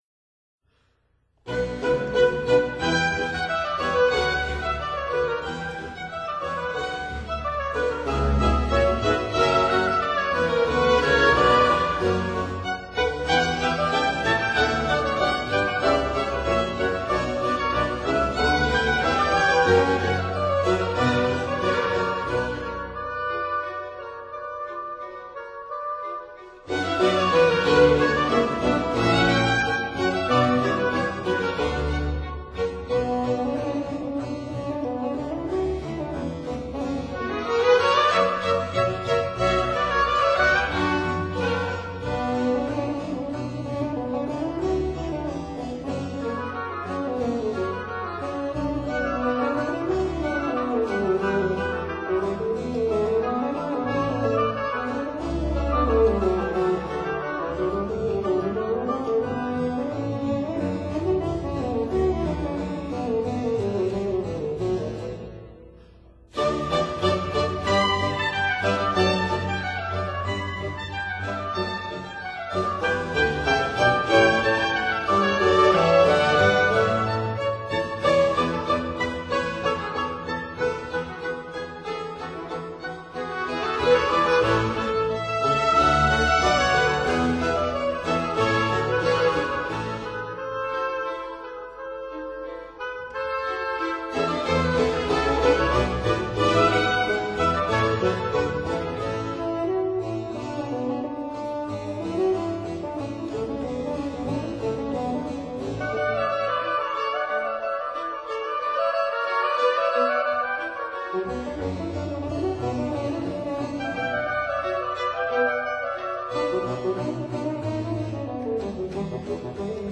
Concertos for Wind Instruments
oboe
flute
bassoon
violin
viola
violone
harpsichord